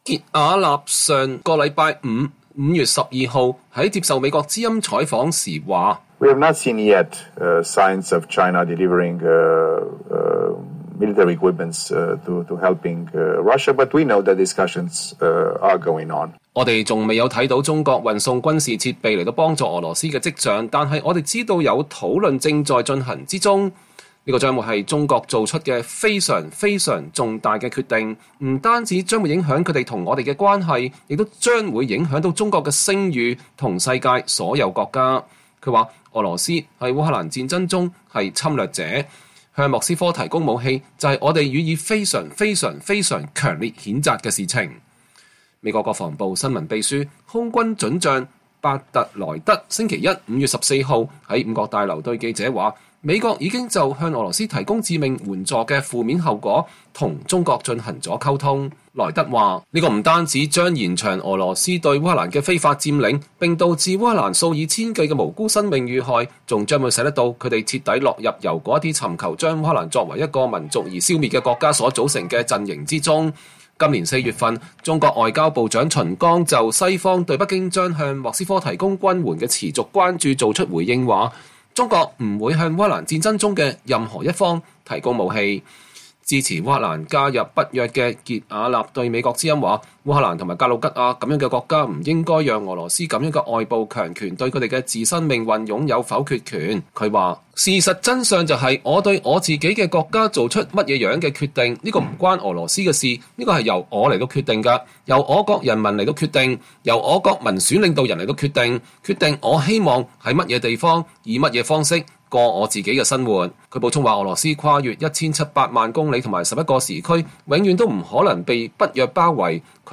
北約副秘書長接受VOA專訪 告誡中國不要向俄羅斯提供武器